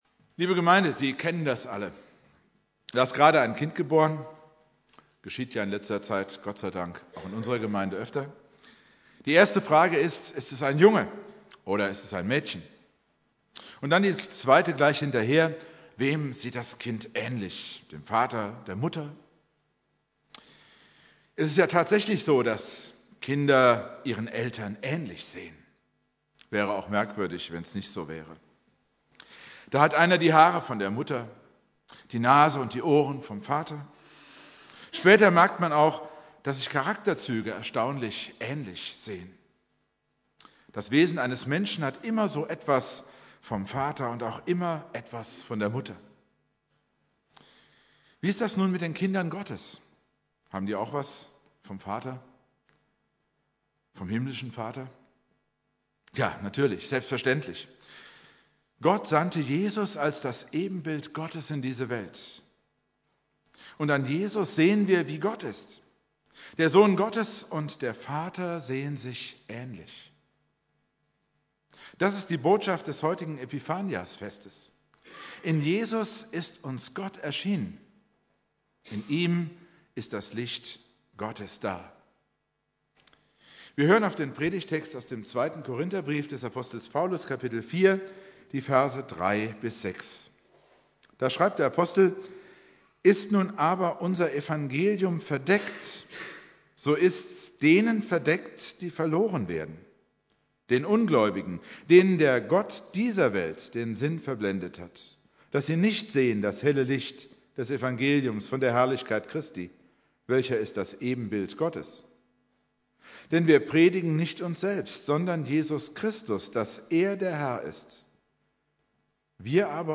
Themenpredigten